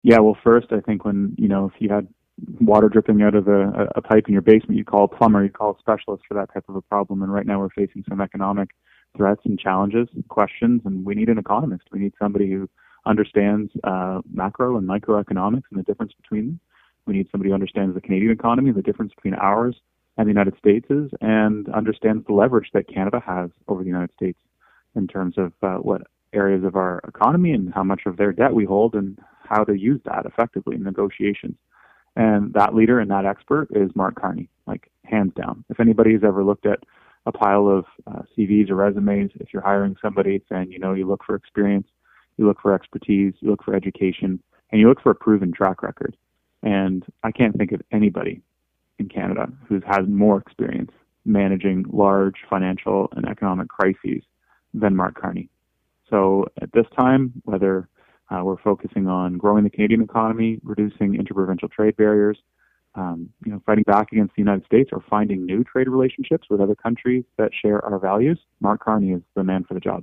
Here’s our interview: